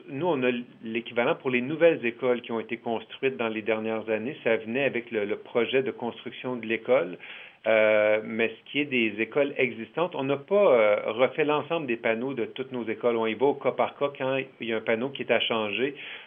En entrevue au service de nouvelles de M105